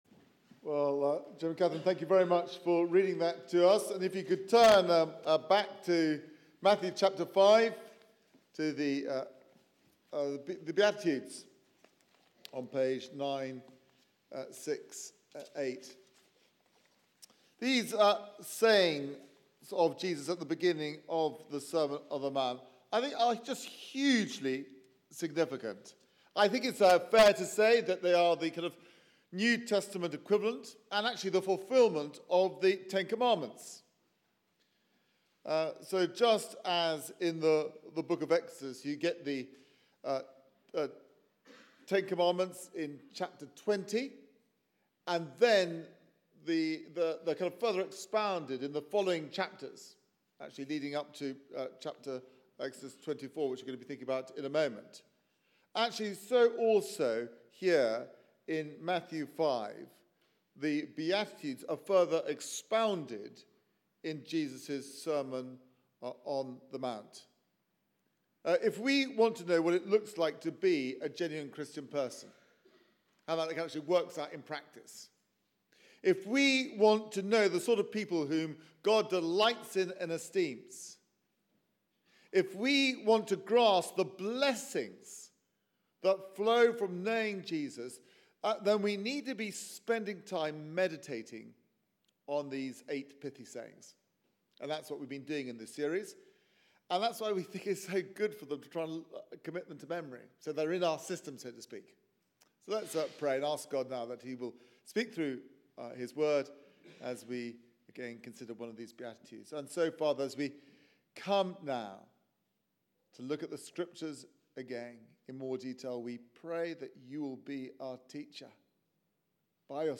Media for 11am Service on Sun 20th Nov 2016
Series: The Beatitudes Theme: Blessed are the pure in heart Sermon